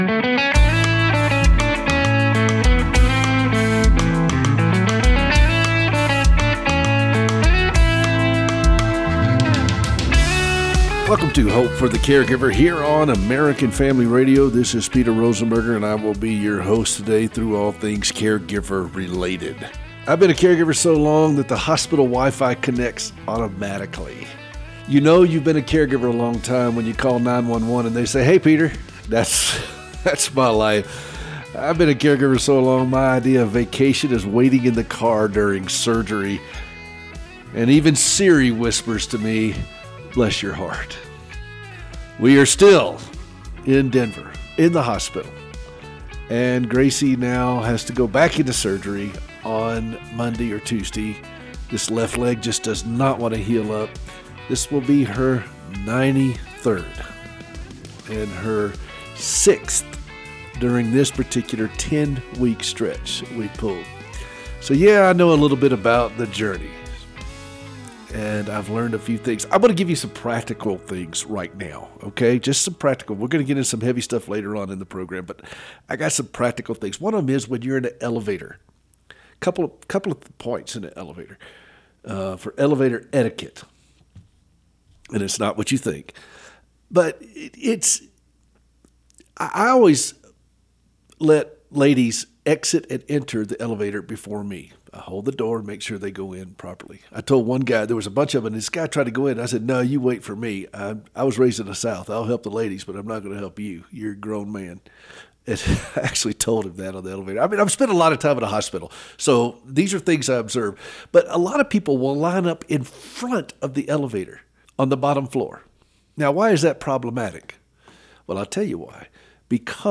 is a weekly broadcast supporting and strengthening fellow caregivers.